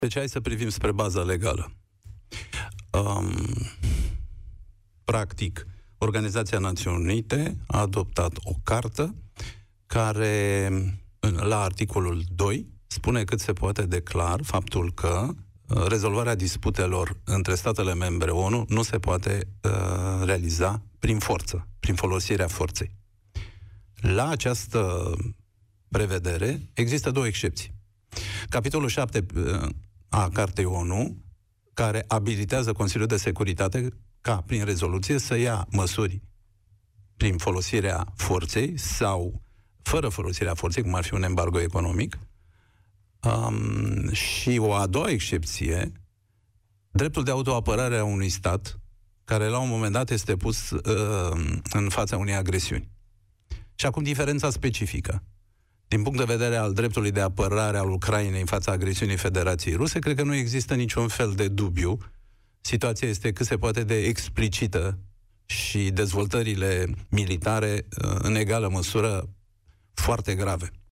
Cristian Diaconescu, fost ministru de externe al României, este invitatul lui Cătălin Striblea în emisiunea „România în Direct”.